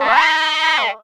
Cri de Tiboudet dans Pokémon Soleil et Lune.